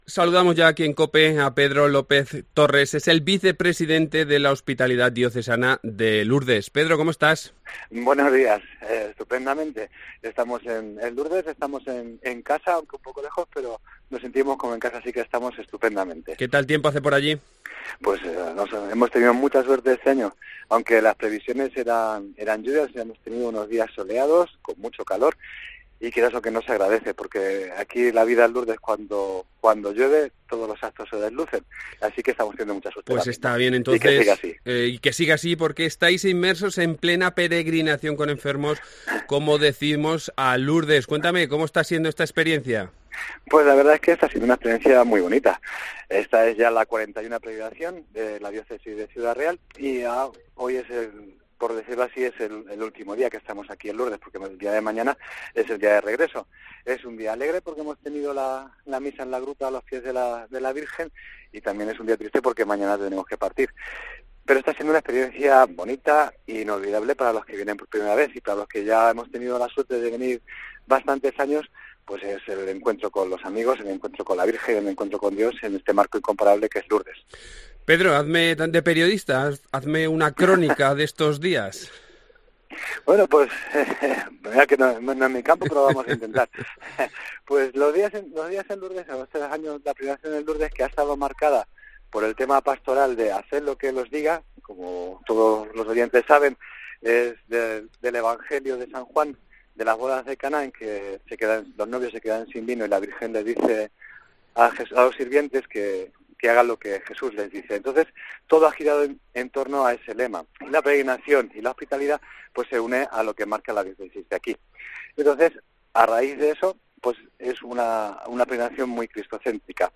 Ciudad Real